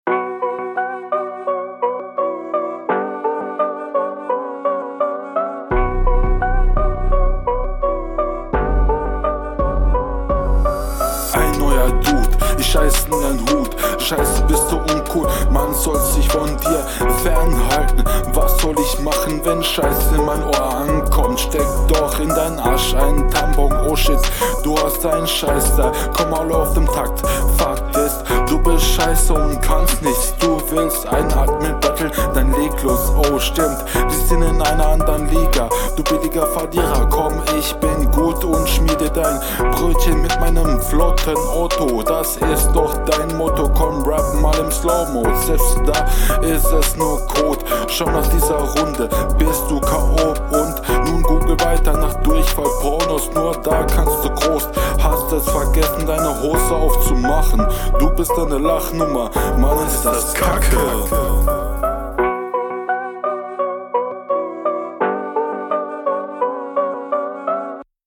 Dein Flow ist eine Katastrophe, als Hörer ist man sofort raus.